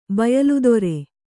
♪ bayaludore